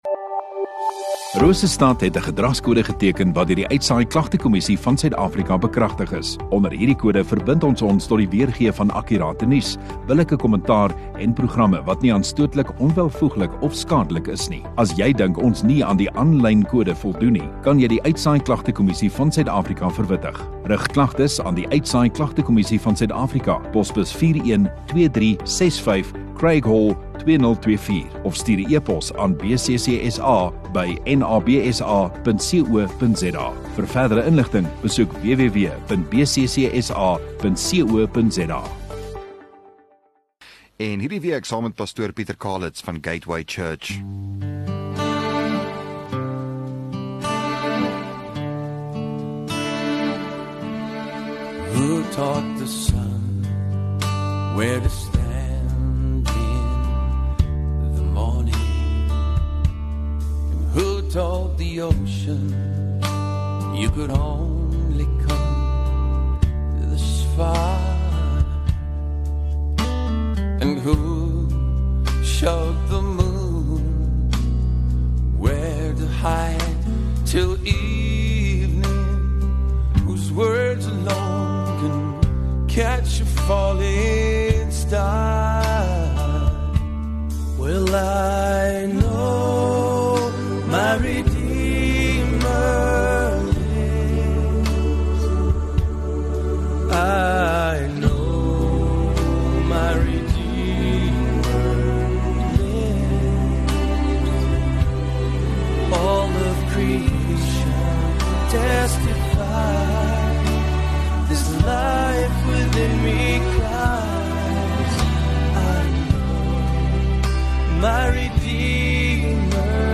5 Jun Donderdag Oggenddiens